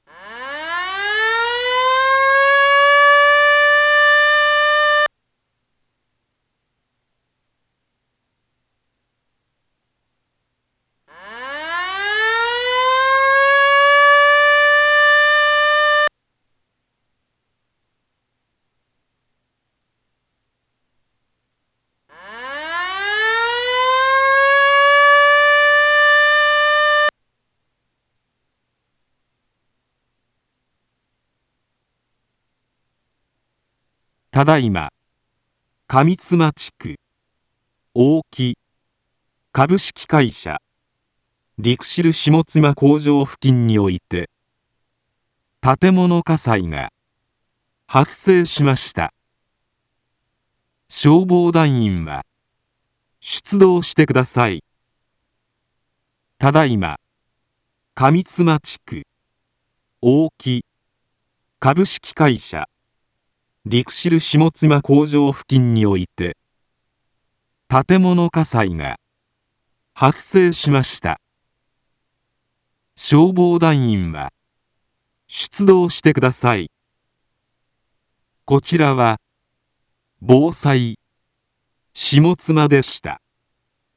一般放送情報
Back Home 一般放送情報 音声放送 再生 一般放送情報 登録日時：2023-08-20 06:08:28 タイトル：火災報 インフォメーション：ただいま、上妻地区、大木、かぶしきかいしゃリクシルしもつまこうじょう 付近において、 建物火災が、発生しました。